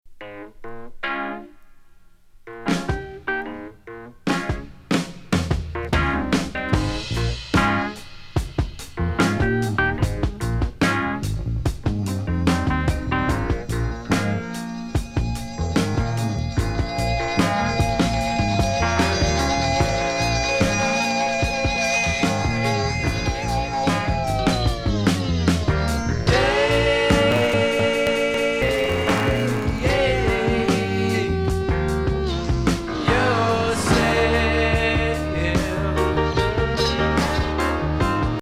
サイケデリック・カントリーブルーズ